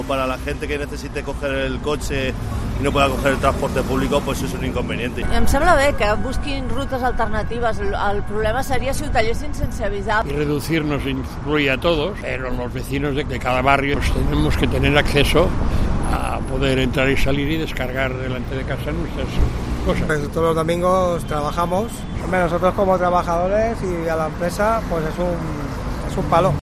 Varias opiniones de los barceloneses